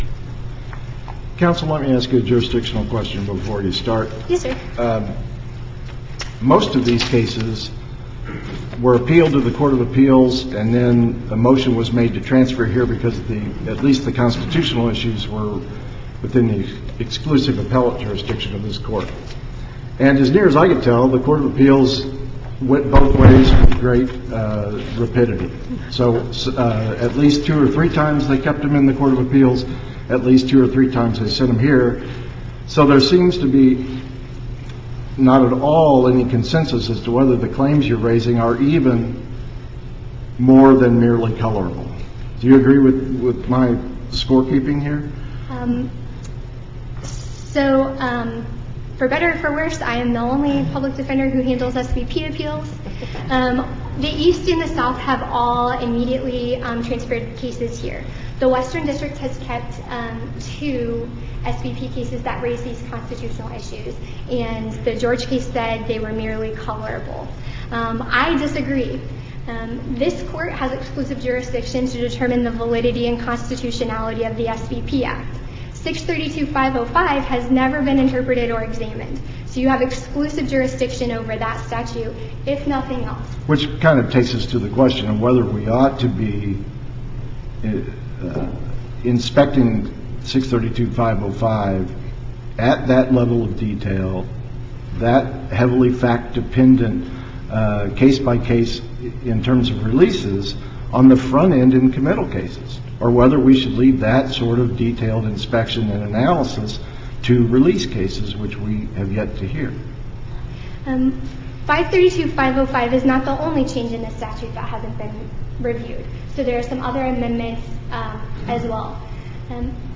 MP3 audio file of oral arguments in SC96076